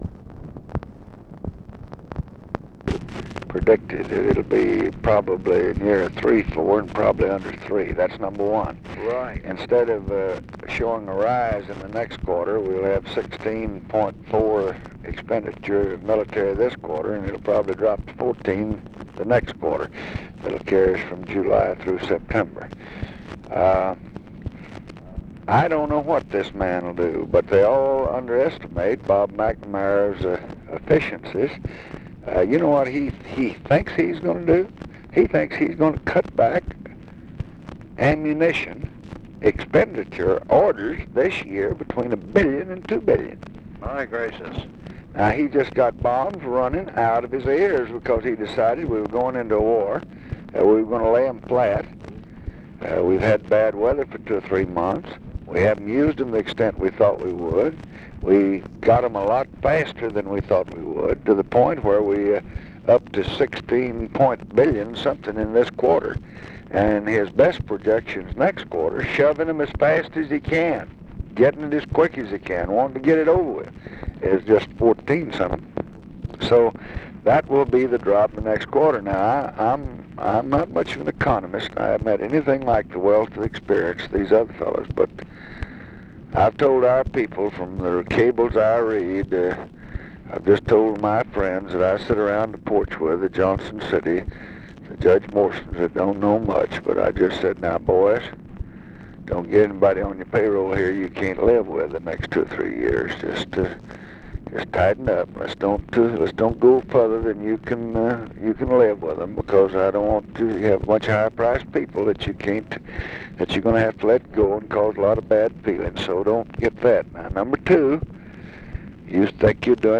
Conversation with WILLIAM MCC. MARTIN and OFFICE NOISE, June 30, 1966
Secret White House Tapes | Lyndon B. Johnson Presidency Conversation with WILLIAM MCC.